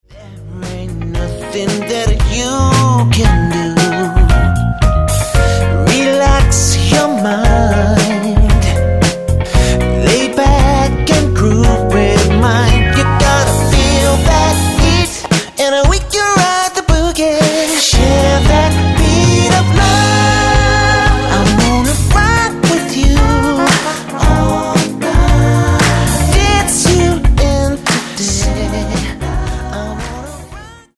Tonart:Ebm-Em Multifile (kein Sofortdownload.
Die besten Playbacks Instrumentals und Karaoke Versionen .